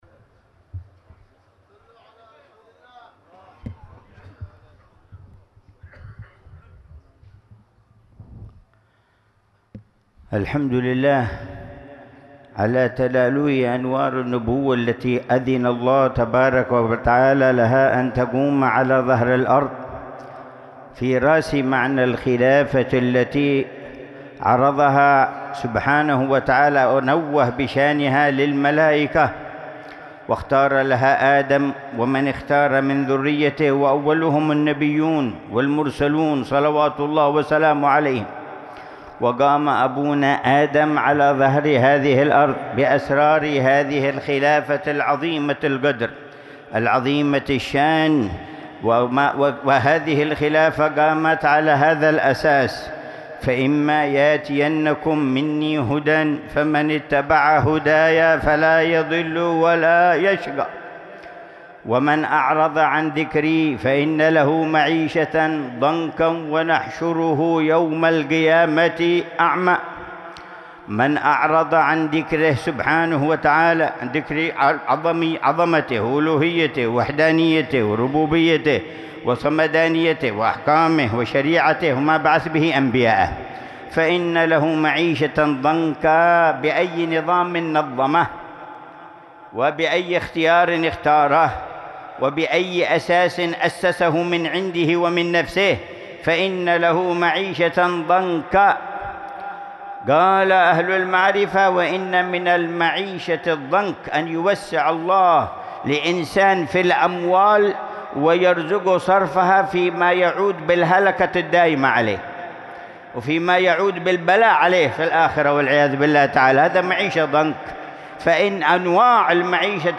مذاكرة
في مجلس الوعظ والتذكير